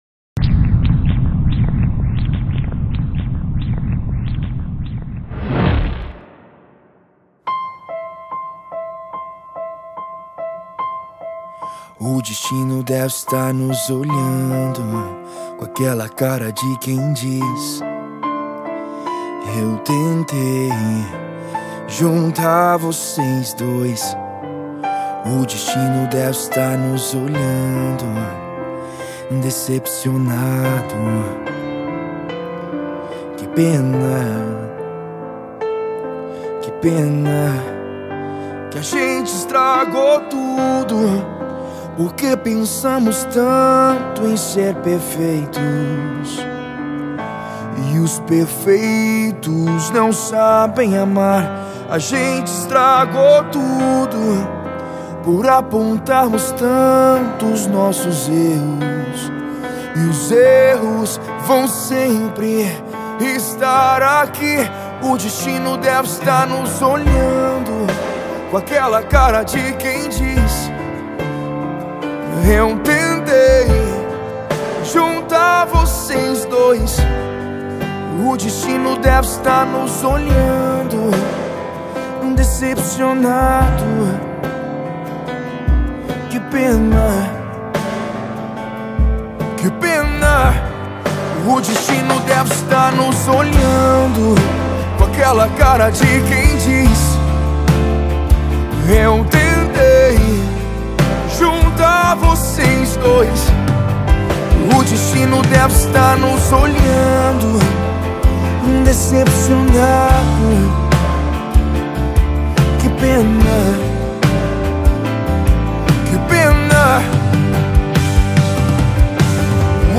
2025-01-31 21:01:59 Gênero: Sertanejo Views